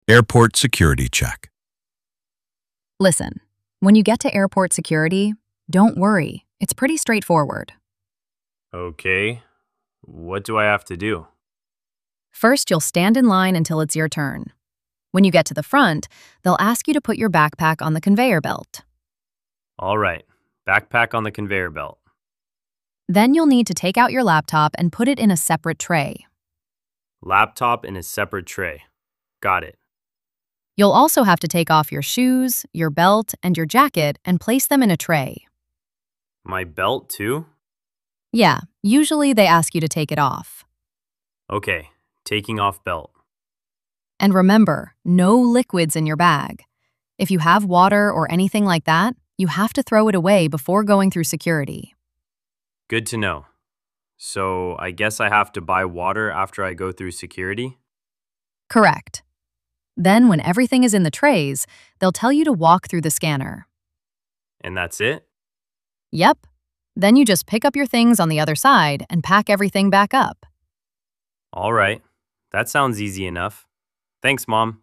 Dictation – Airport Security Check
Security Check (Mother explaining to her son)